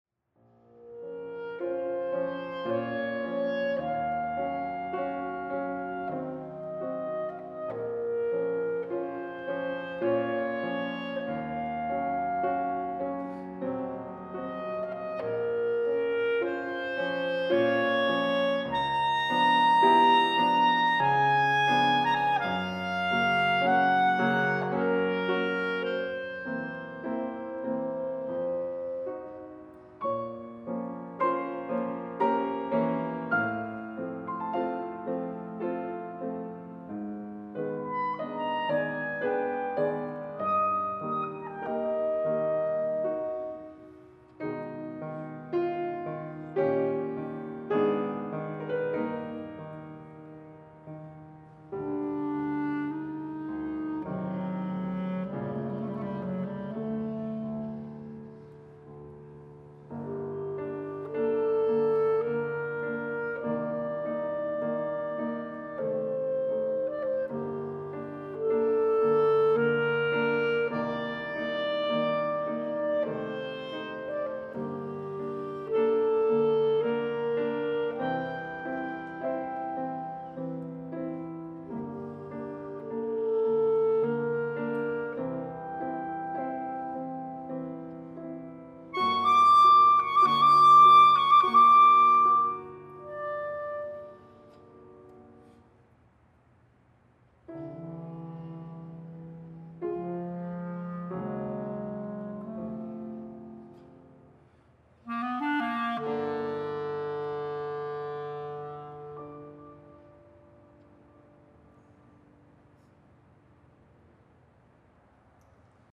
in performance:
mp3 Sonata mvt. 2 (Poulenc) Bb clarinet 4.4MB
Poulenc_clarinet_Sonata2.mp3